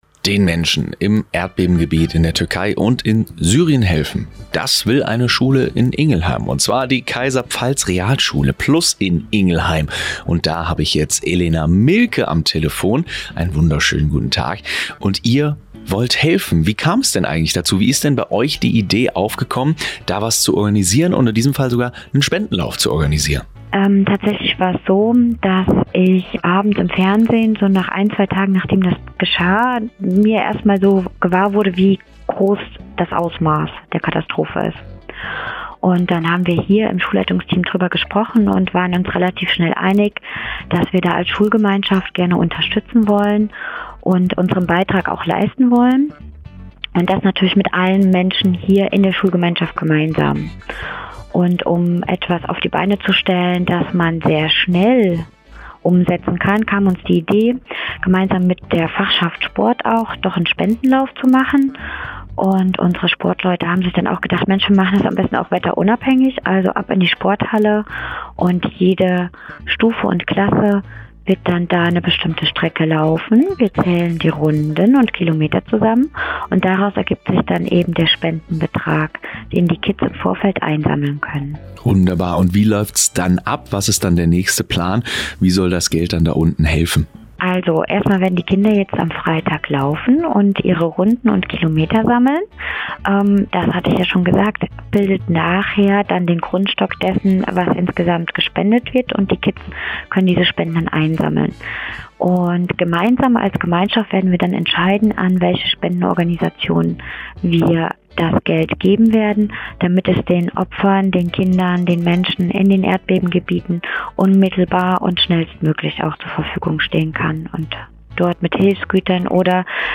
Interview des Radiosenders “Antenne Mainz”